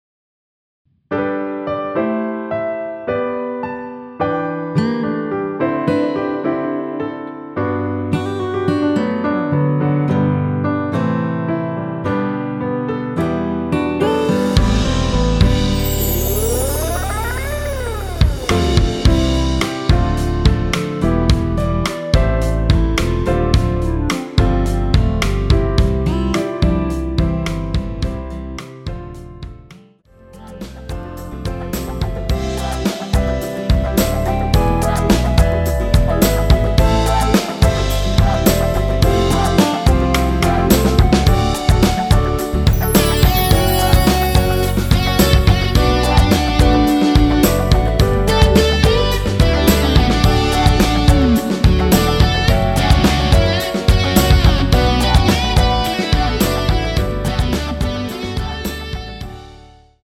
원키에서(-7)내린 멜로디 포함된 MR입니다.
멜로디 MR이라고 합니다.
앞부분30초, 뒷부분30초씩 편집해서 올려 드리고 있습니다.
중간에 음이 끈어지고 다시 나오는 이유는